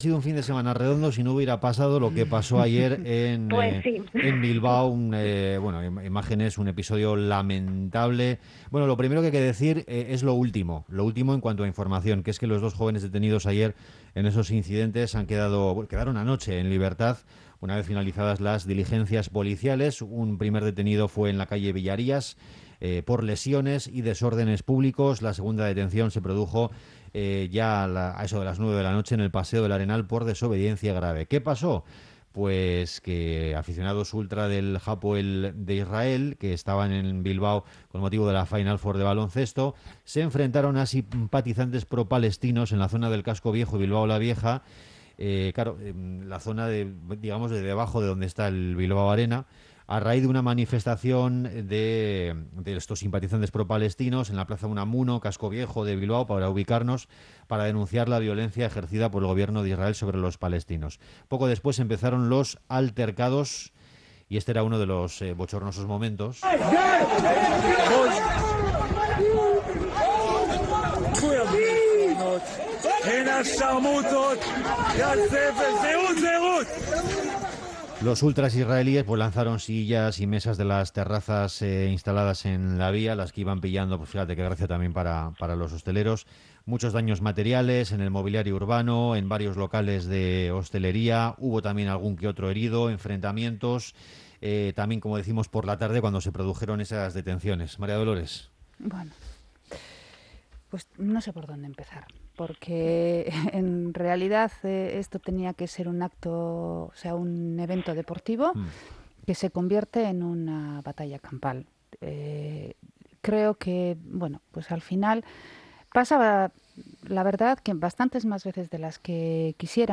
Los altercados en Bilbao protagonizan nuestra tertulia en Onda Vasca
Morning show conectado a la calle y omnipresente en la red.